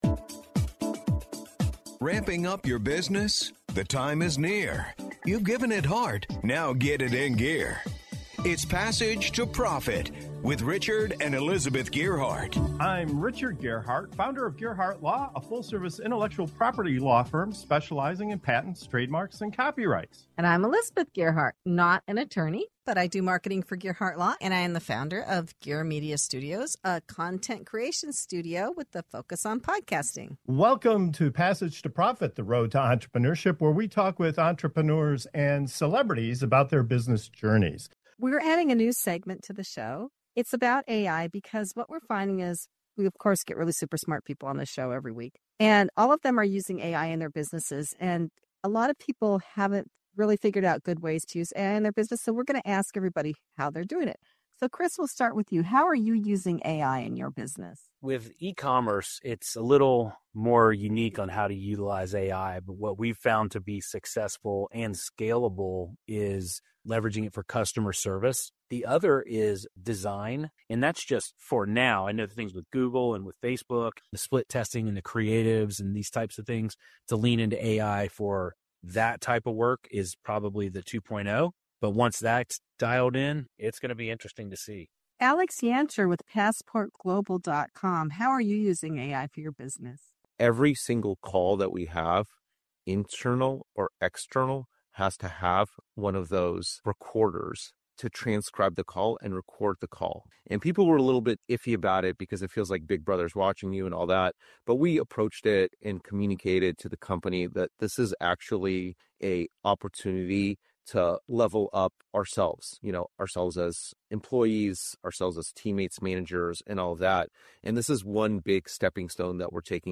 In this segment of "AI in Business" on Passage to Profit Show, founders and leaders share exactly how they’re using AI to scale customer service, streamline design, improve team communication, and even evaluate sales calls. From saving $100K a year to tackling cybersecurity risks and managing legal workflows, you’ll hear the wins, the struggles, and the surprising ways AI is transforming business right now.